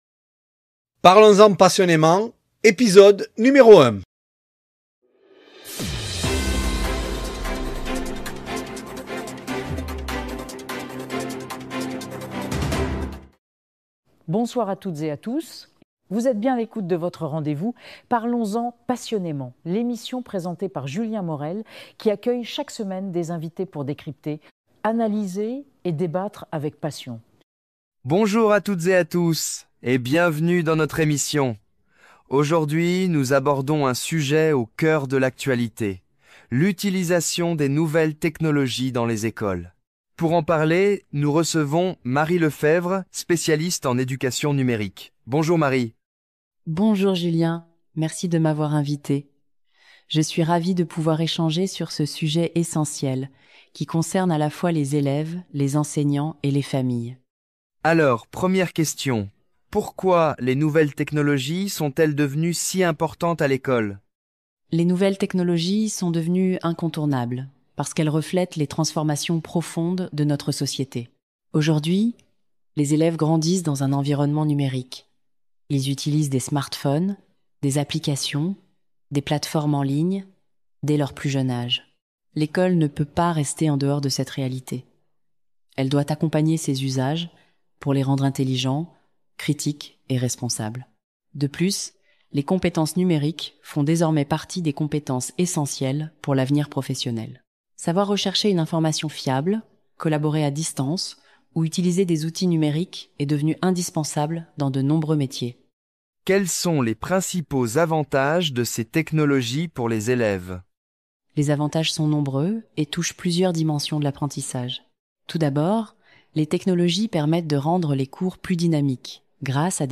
Voici le premier épisode d’émission de radio. Le thème aujourd’hui est celui des nouvelles technologies dans les écoles.